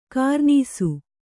♪ kārnīsu